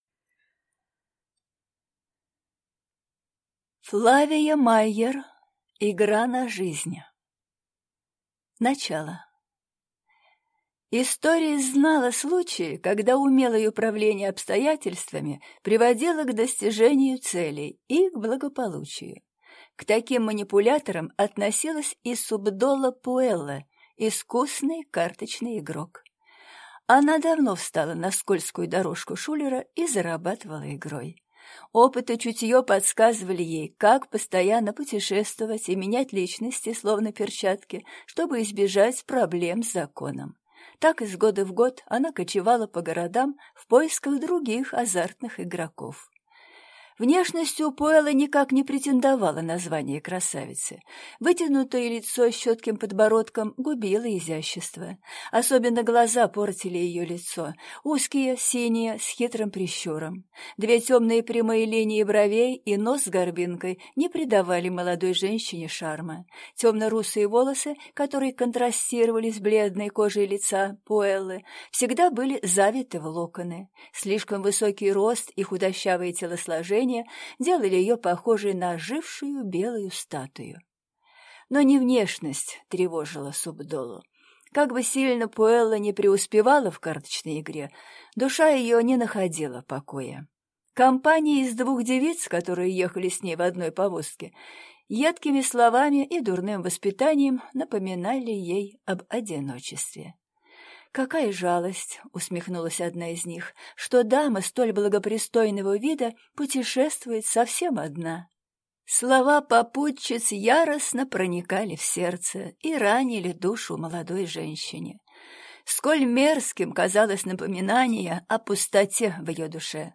Аудиокнига Игра на жизнь | Библиотека аудиокниг
Прослушать и бесплатно скачать фрагмент аудиокниги